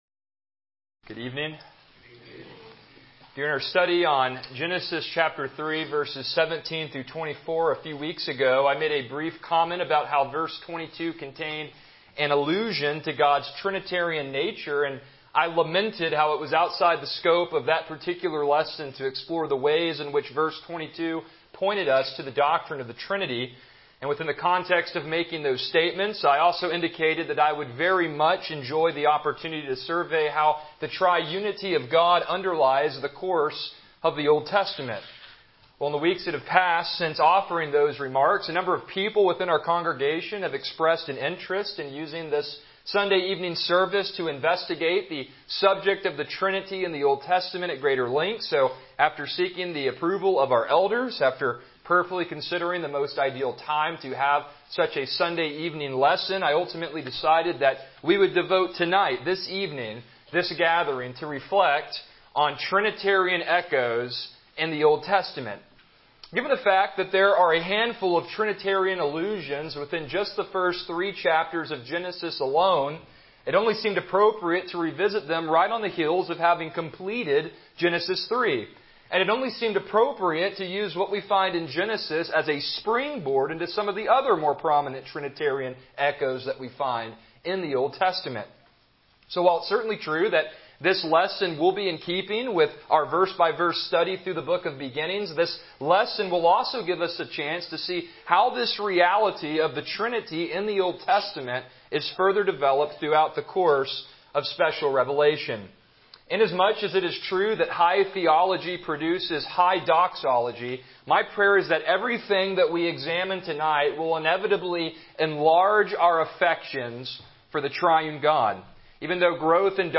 Genesis: Book of Beginnings Service Type: Evening Worship